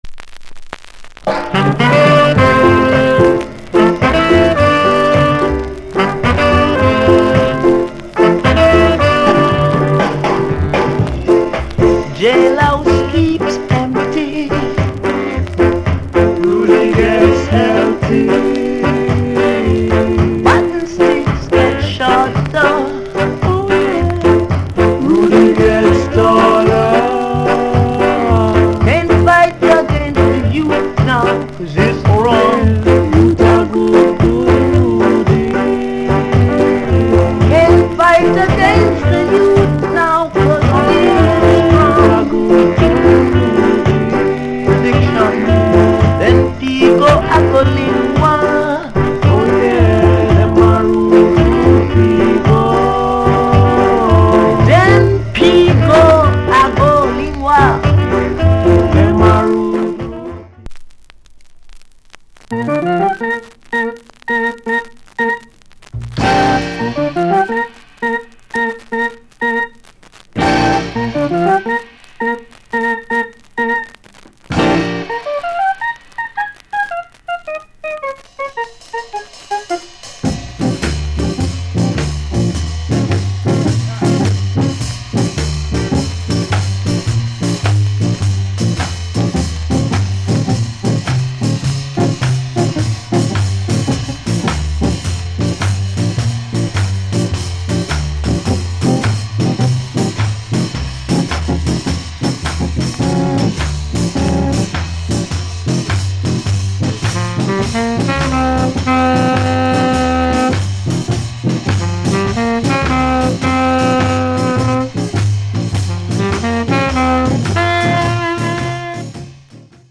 Male Vocal Group Vocal